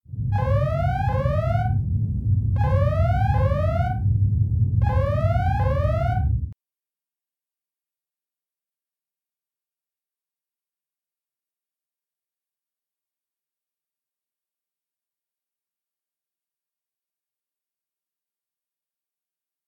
redalert.ogg